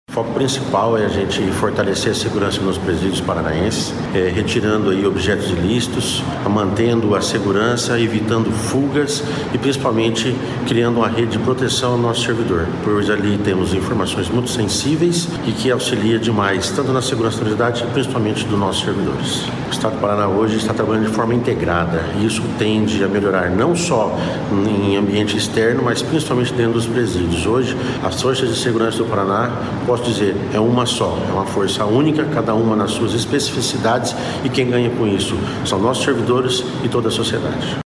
Sonora do diretor-geral da Polícia Penal do Paraná, Reginaldo Peixoto, sobre a Operação Mute para combate a comunicação ilícita em unidades prisionais do Paraná